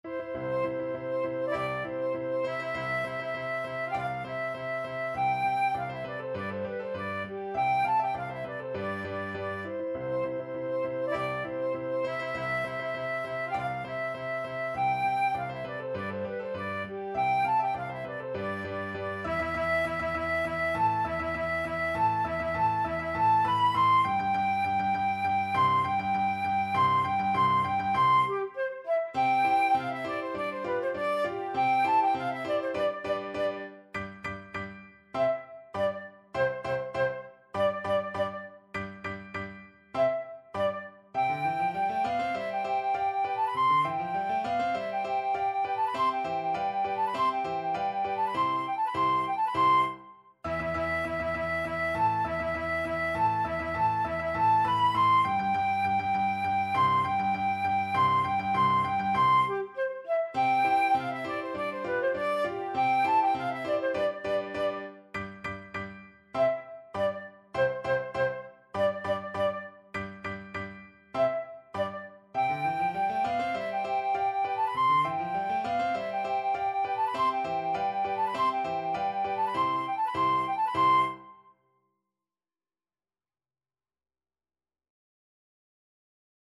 Classical Beethoven, Ludwig van Yorckscher March (2 Marches for Military Band, WoO 18, No. 1) Flute version
Flute
C major (Sounding Pitch) (View more C major Music for Flute )
March = c.100
Classical (View more Classical Flute Music)